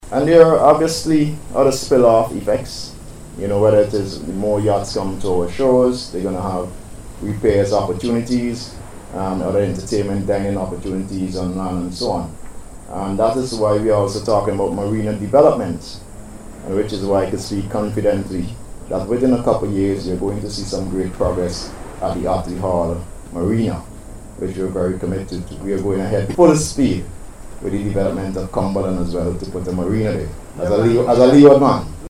The issue was raised by Minister of Tourism, Sustainable Development, and Civil Aviation Dr. Hon. Kishore Shallow, as he spoke at a media briefing held this week to provide an update on the 2026 Sailing Week.